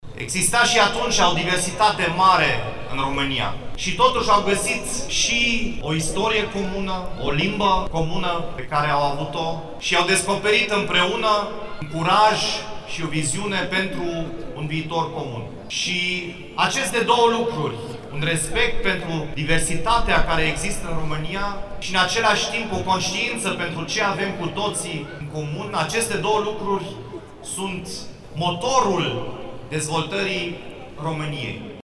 Anul acesta, defilarea de Ziua Națională a României s-a desfășurat la Timișoara, în fața Catedralei Mitropolitane.
Prezent la manifestări, primarul Dominic Fritz a scos în evidență  caracterul multicultural al orașului.